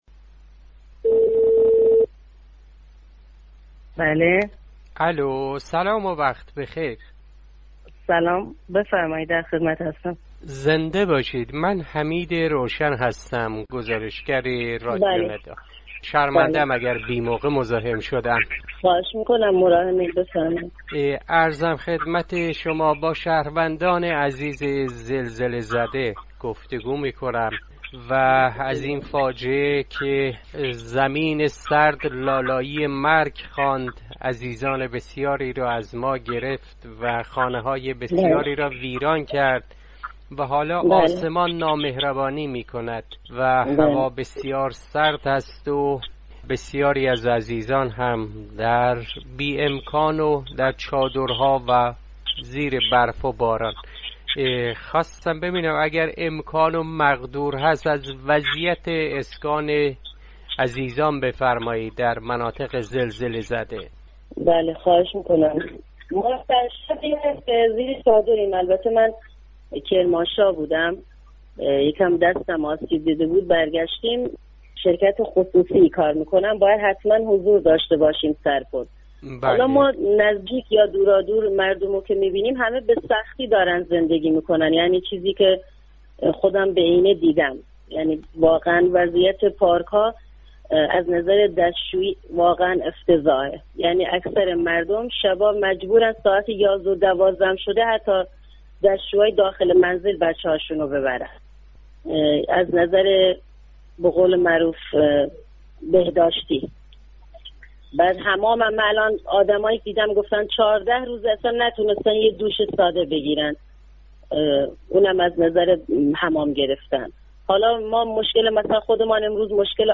يكي از شهروندان زلزله زده در شهرستان سرپل ذهاب در گفتگو با راديو ندا از خسارت هاي زلزله و سختي و مشكلات مردم زلزله زده در منطقه مي گويد.